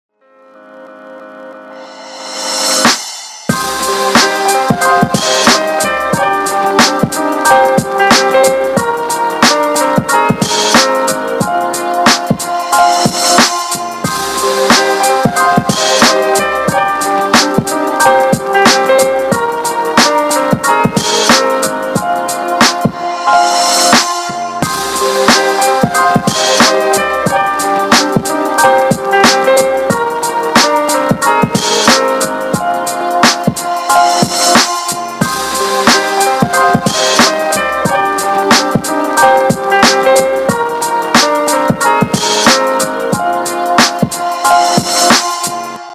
• Качество: 320, Stereo
красивые
Хип-хоп